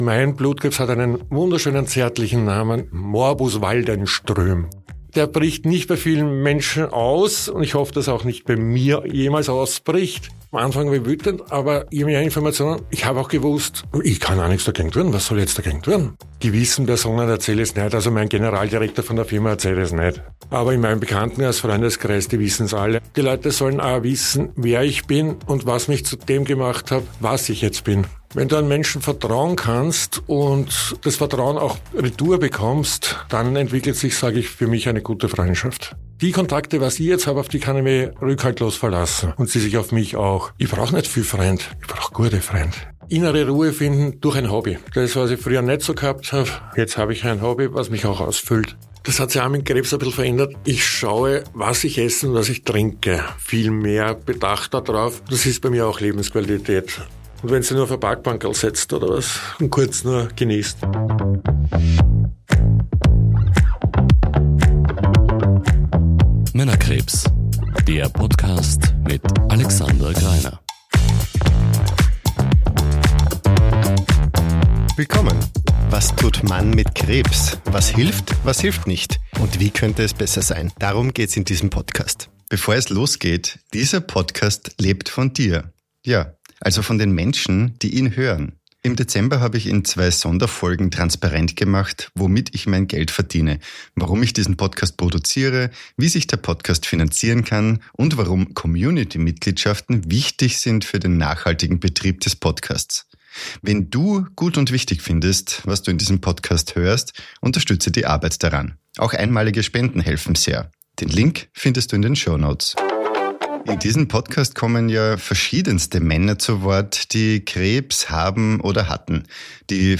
Gespräch) · Folge 34 ~ Männerkrebs – Was tut Mann mit Krebs?